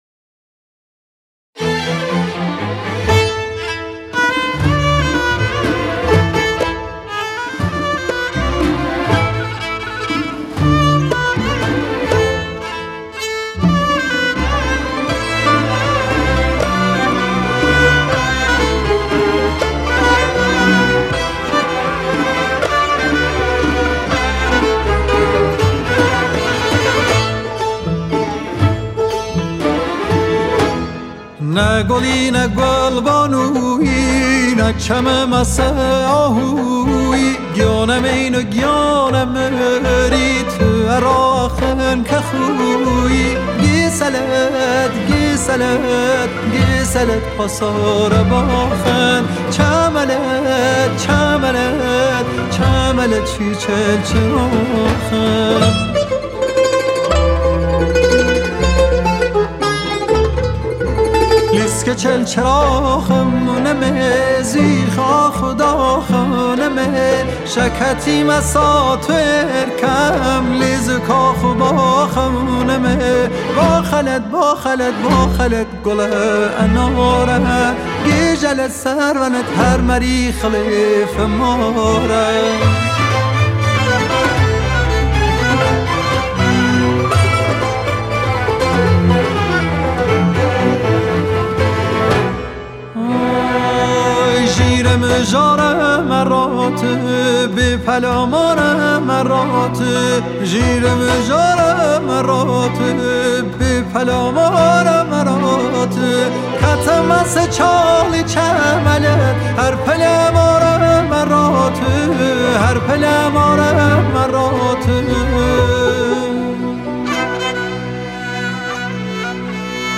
تار
سنتور
کمانچه
کوبه‌ای
ویلون و ویولا
ویلونسل
کنترباس